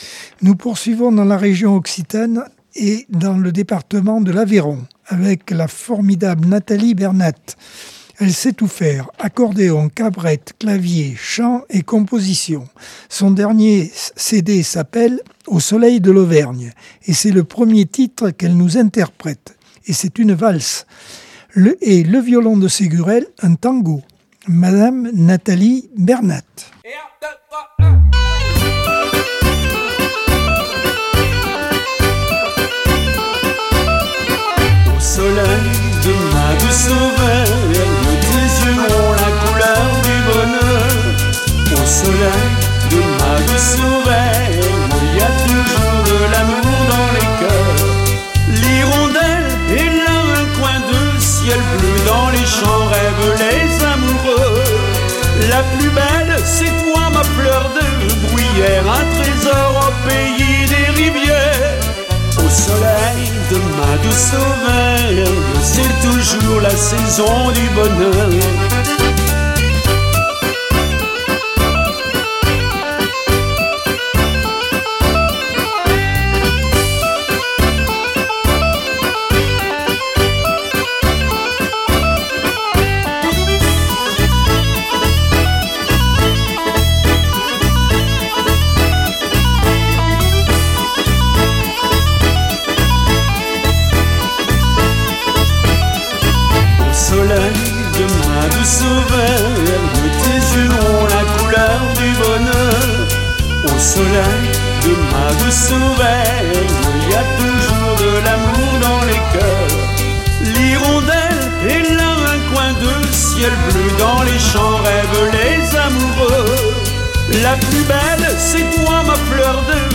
Accordeon 2025 sem 01 bloc 3 par Accordeon 2025 sem 01 bloc 3.